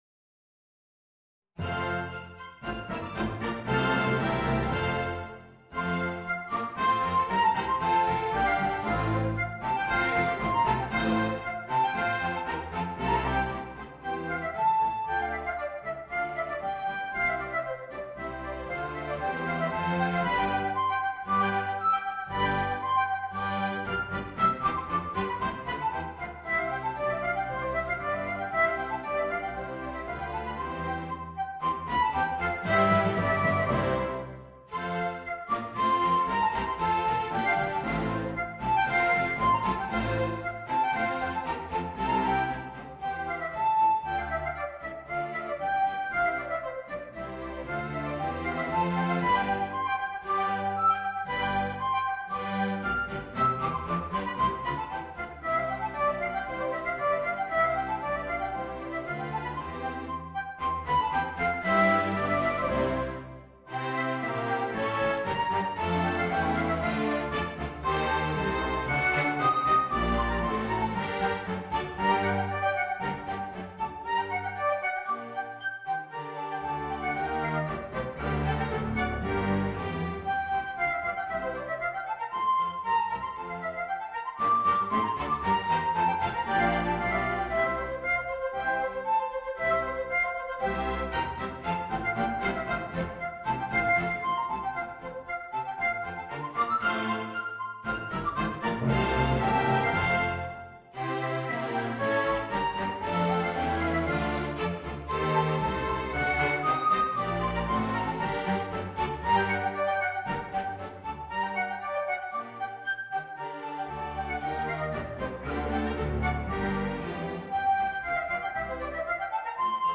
Voicing: Trumpet and Orchestra